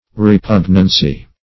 Repugnance \Re*pug"nance\ (-nans), Repugnancy \Re*pug"nan*cy\